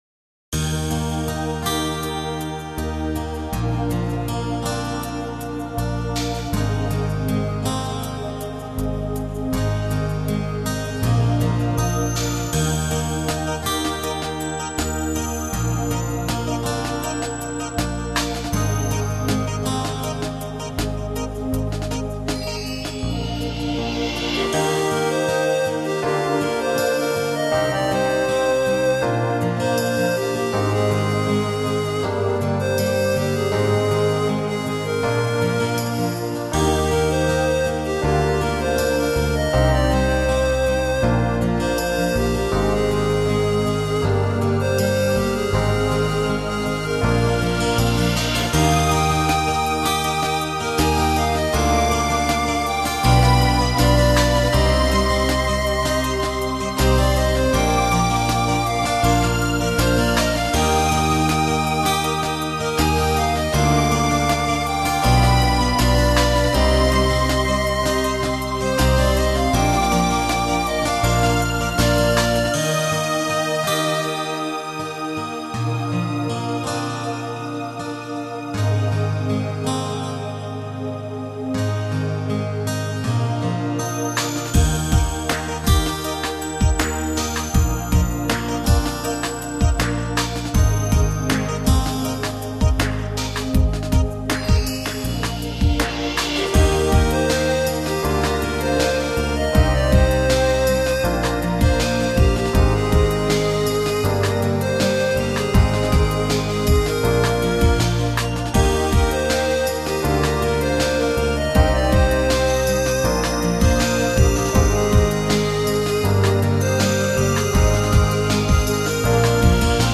◆ 歌モノ ◆